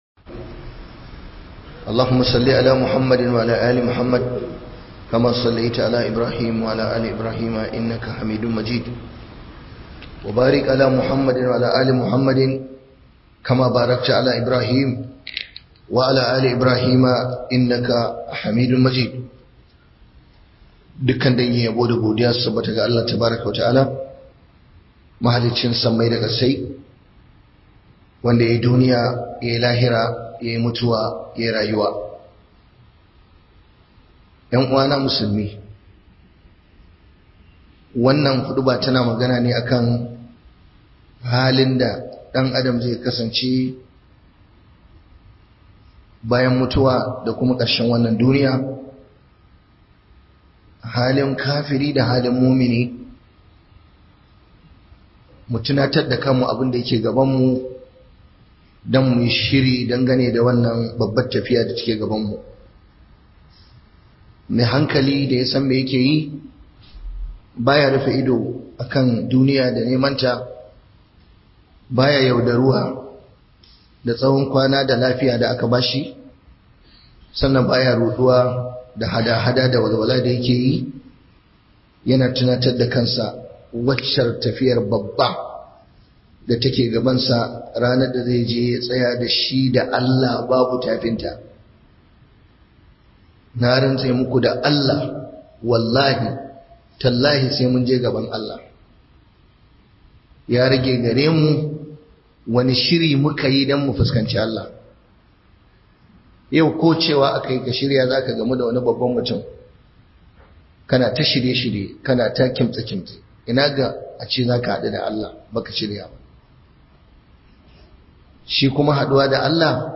Book Huduba